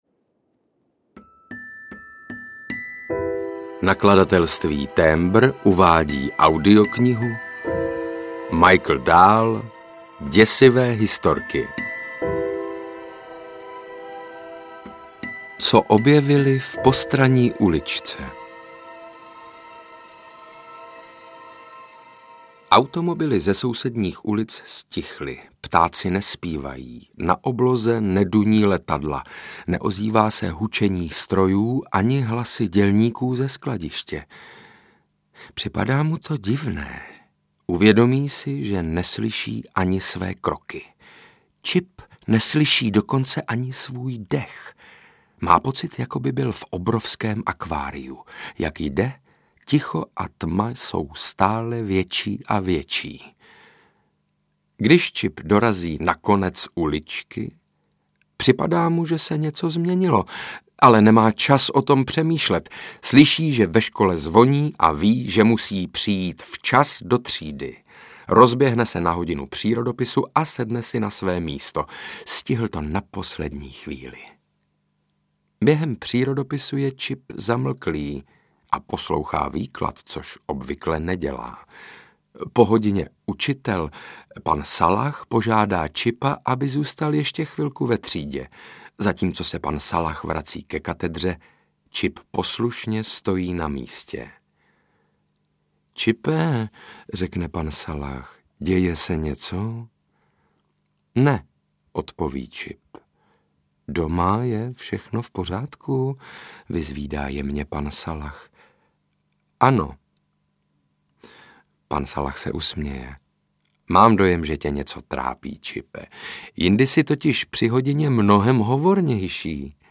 Děsivé historky 1 audiokniha
Ukázka z knihy
• InterpretSaša Rašilov